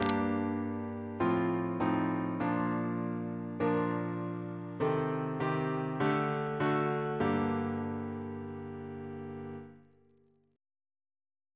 Single chant in G minor Composer: William Croft (1678-1727), Organist of Westminster Abbey Reference psalters: ACB: 103; CWP: 183; H1940: 617; H1982: S425; OCB: 117 150; RSCM: 171